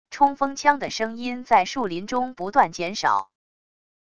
冲锋枪的声音在树林中不断减少wav音频